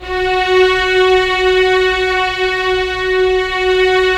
Index of /90_sSampleCDs/Roland L-CD702/VOL-1/STR_Vlns 1 Symph/STR_Vls1 Sym slo